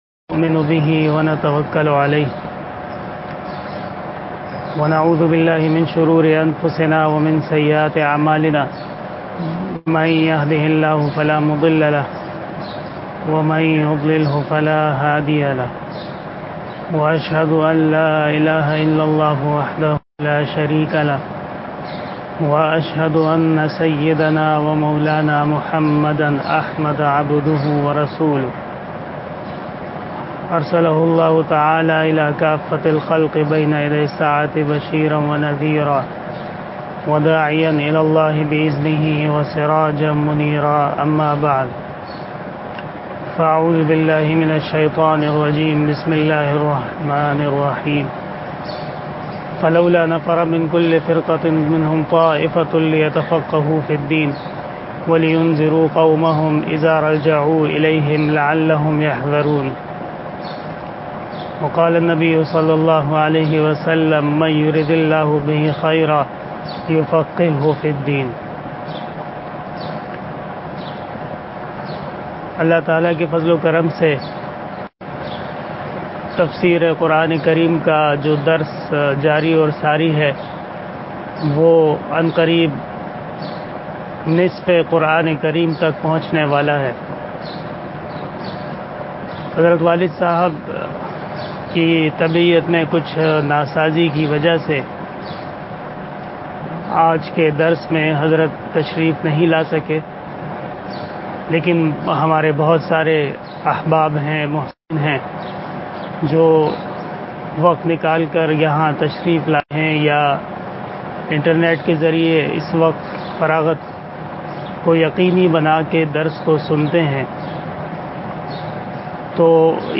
35 Tafseer Day Bayan 05 May 2020 (11 Ramazan 1441 HJ)Tuesday